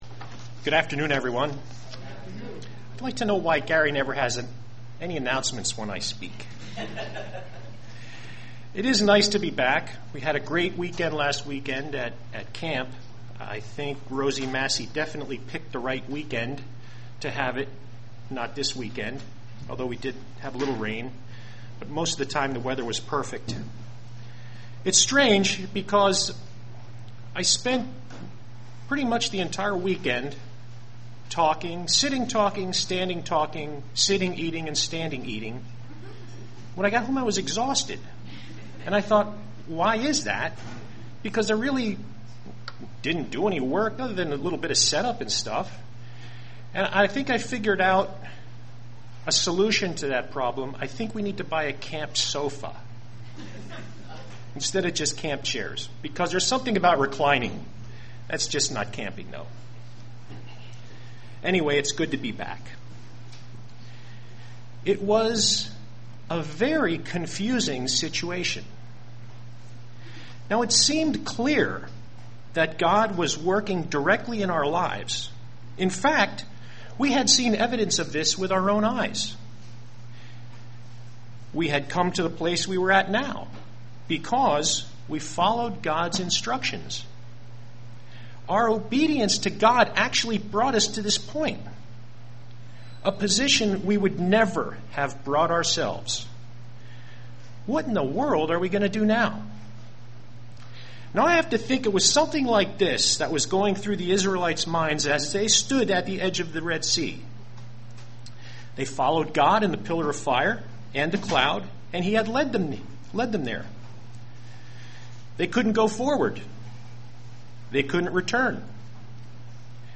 Given in Lehigh Valley, PA
UCG Sermon Studying the bible?